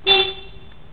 ラジエターとフロントグリルの間にショボいホーンを発見。
ショボショボホーンの　「ミ」　から　「ファ」　に半音グレードアップしました。